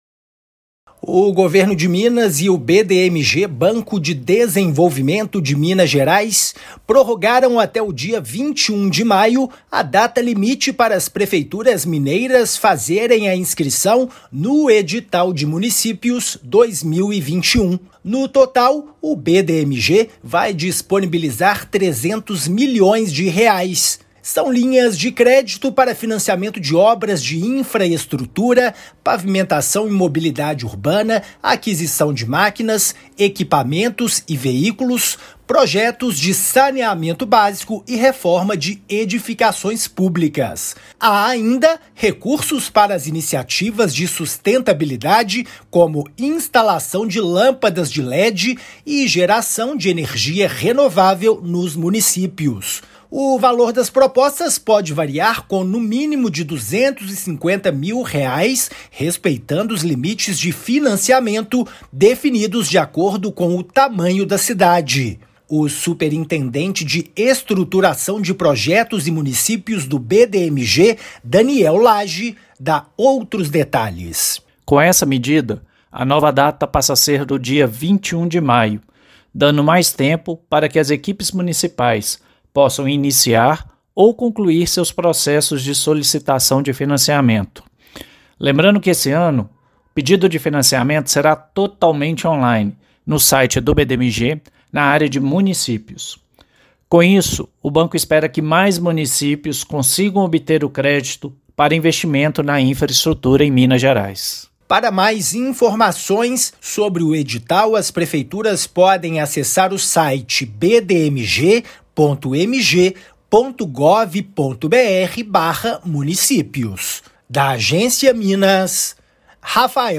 [RÁDIO] BDMG prorroga prazo para prefeituras mineiras solicitarem financiamento
Edital de Municípios, lançado pelo Governo de Minas, disponibiliza R$ 300 milhões para obras de infraestrutura e melhoria dos serviços públicos. Ouça a matéria de rádio.
MATÉRIA_RÁDIO_BDMG_MUNICIPIOS.mp3